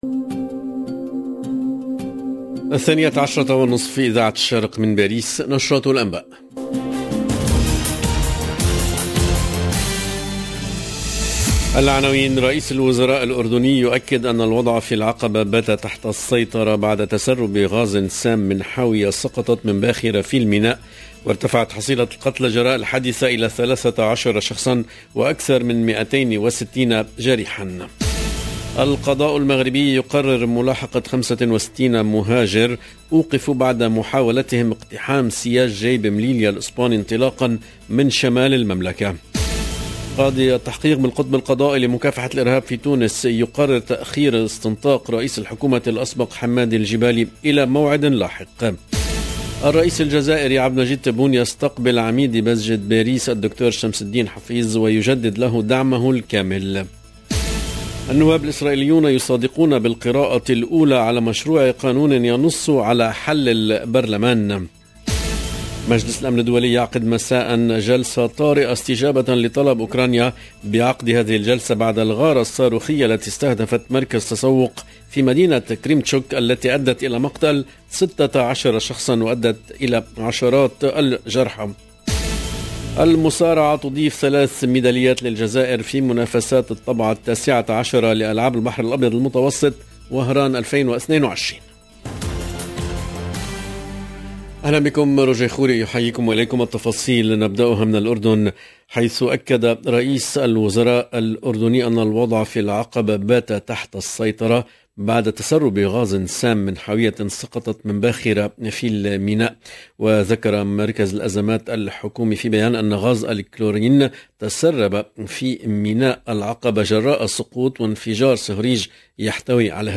LE JOURNAL DE MIDI 30 EN LANGUE ARABE DU 28/06/22 LB JOURNAL EN LANGUE ARABE